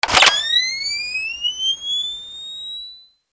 На этой странице представлены звуки мин – от глухих подземных взрывов до резких срабатываний нажимных механизмов.
Звук срабатывания мины при приближении